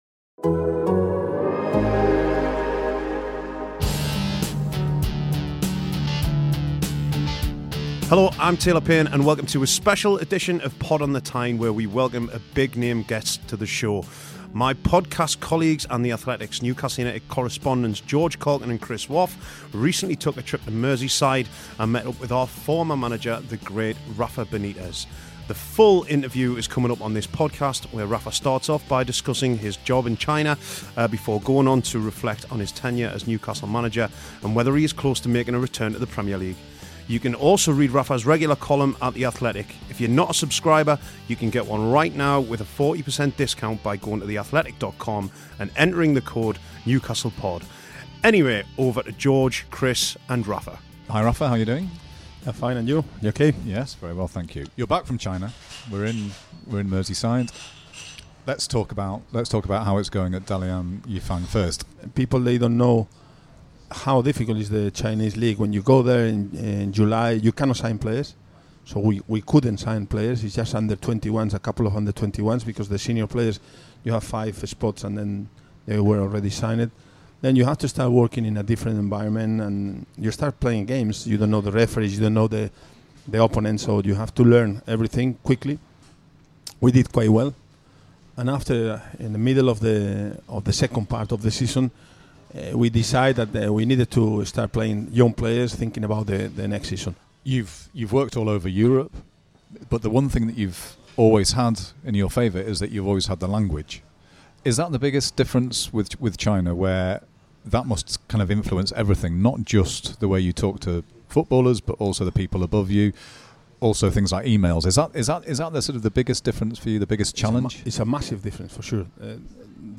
Rafa Benitez - An Exclusive Interview